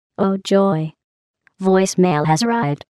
I thought I'd share these cool Portal ringtones for any Portal fans out there.  8)
Voice Mail:
GlaDOS-VoiceMail.mp3